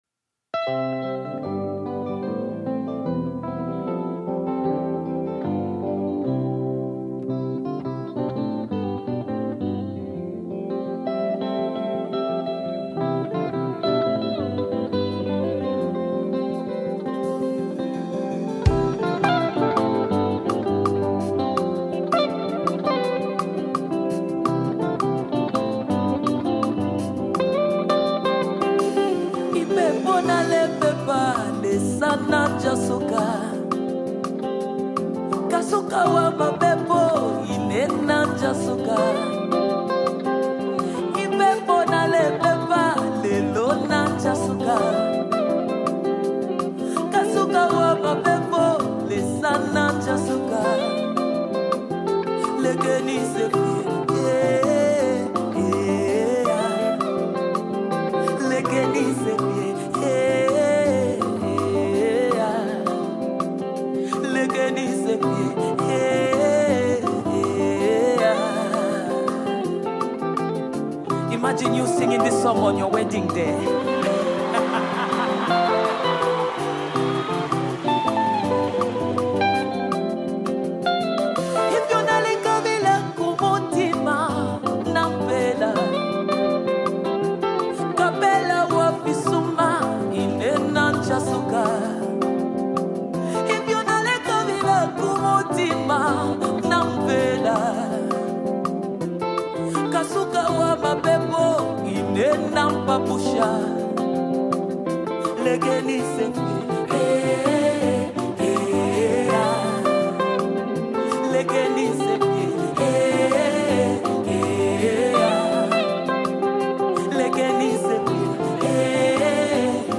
have joined forces to release a powerful new worship song
gospel song
With heartfelt vocals
• Genre: Gospel